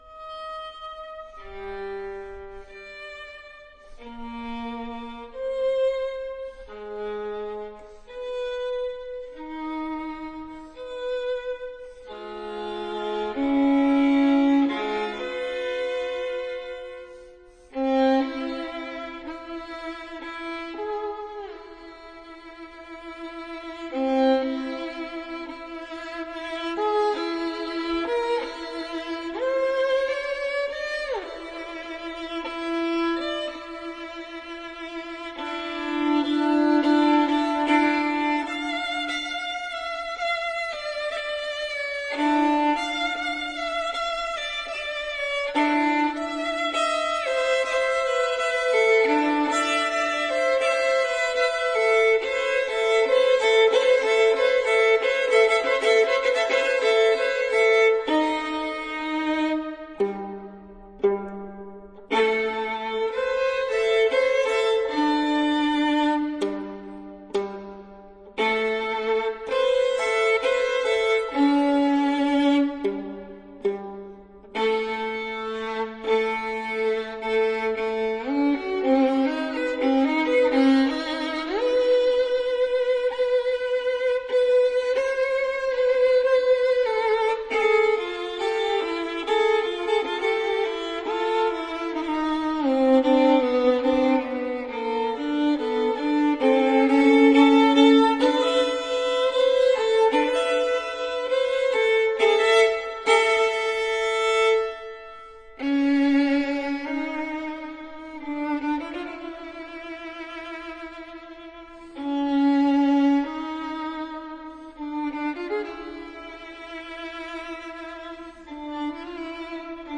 這個曲子，就更有20世紀的風格。